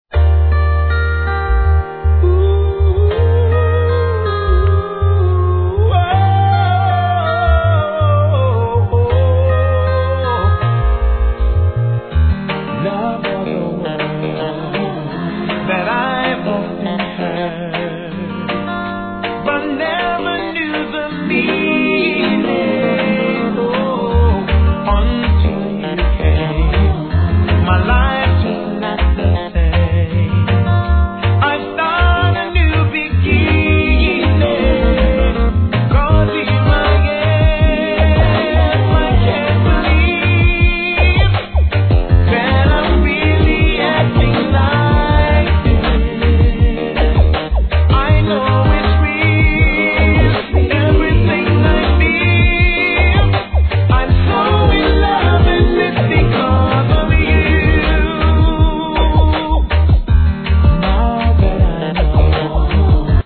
REGGAE
美メロNICEミディアム♪